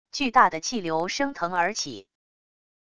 巨大的气流升腾而起wav音频